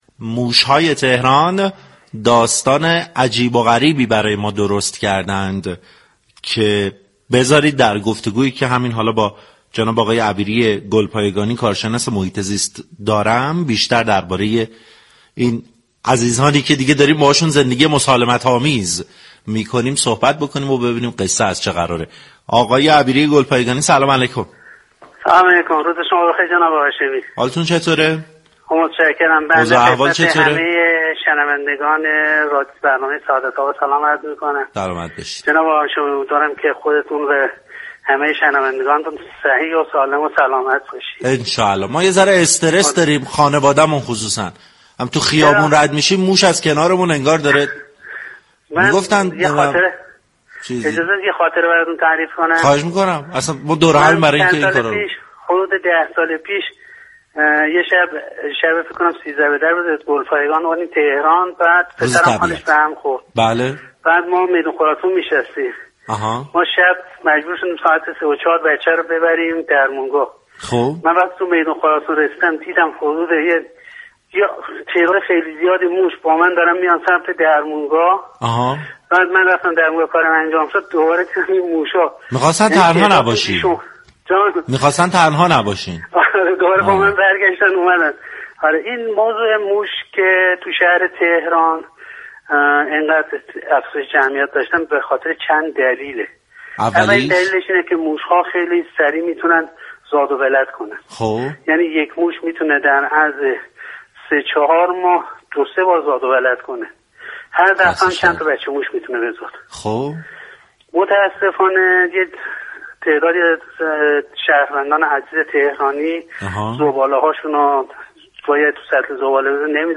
در گفت‌وگو با سعادت آباد رادیو تهران